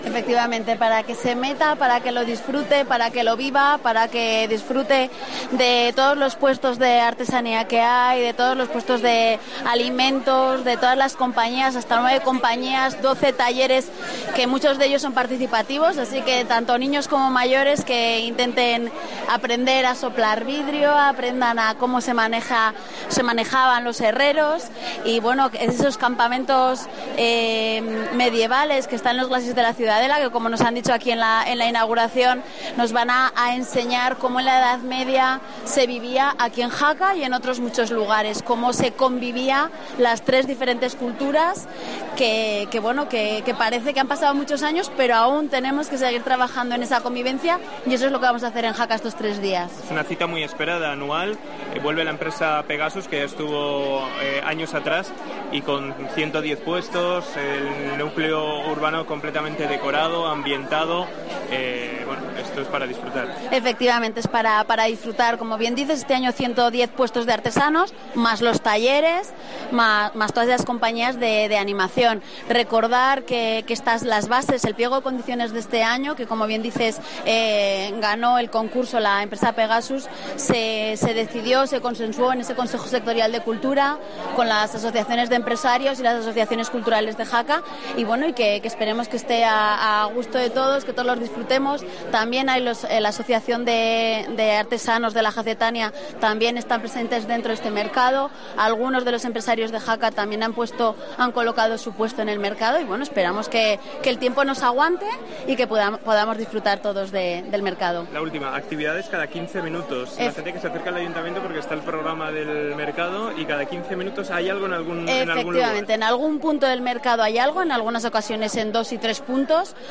Entrevista Susana Lacasa
Escucha la entrevista que hemos mantenido con la concejal de cultura Susana Lacasa.